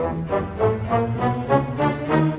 Rock-Pop 20 Orchestra 02.wav